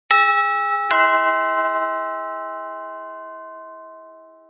doorbell.mp3